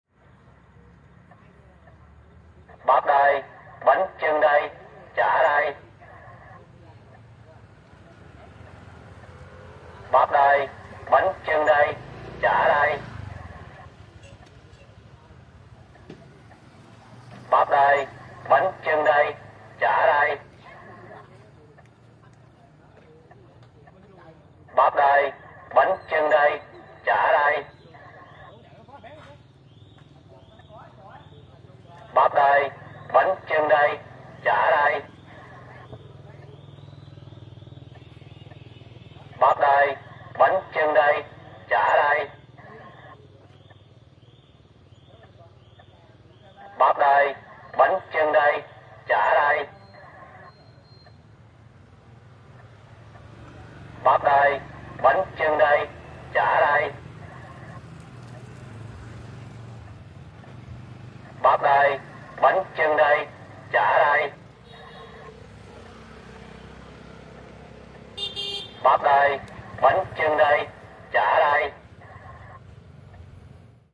描述：2018年3月19日 一位在会安出售自行车小吃的老人。傍晚，晚上7点左右。
标签： 隧道 交通 越南 自行车 喊话器 现场录音 小吃
声道立体声